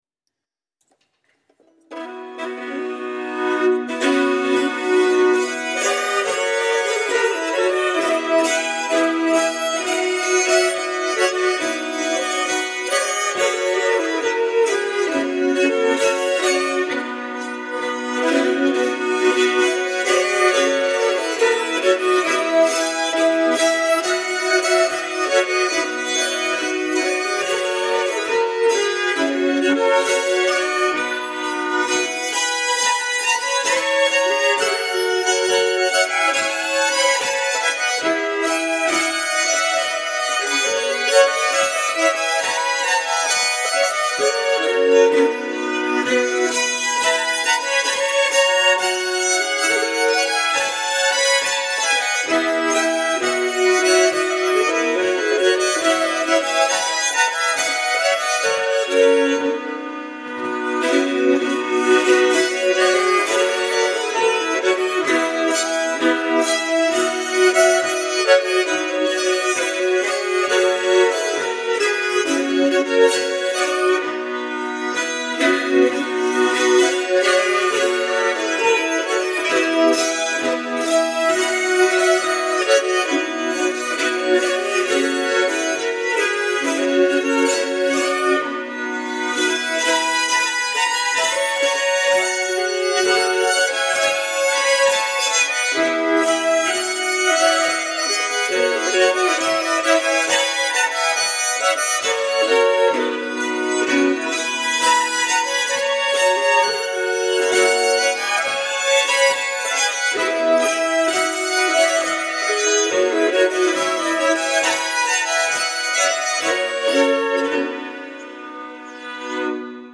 6 Väddö Brudmarsch av Hans Röjås (Hardinfela)